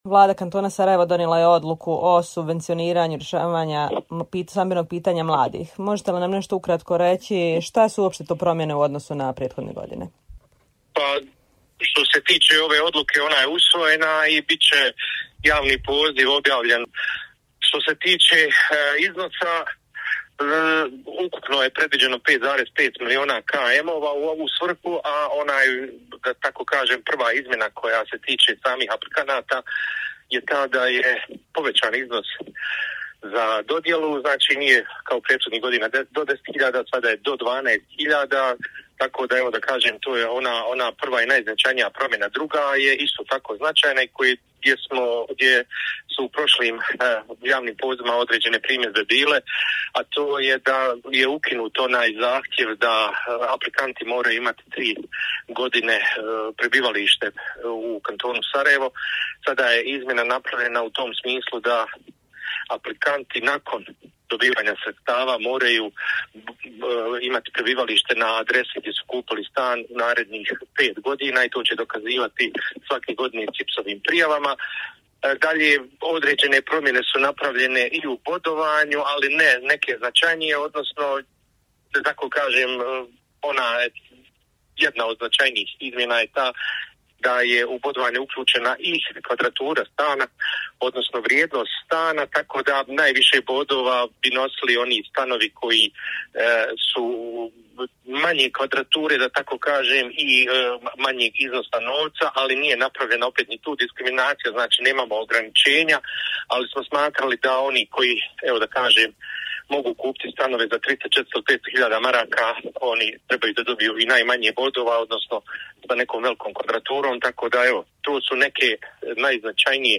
Detalje o ovogodišnjem javnom pozivu, rekao nam je ministar komunalne privrede, infrastrukture, prostornog uređenja, građenja i zaštite okoliša KS Almir Bečarević.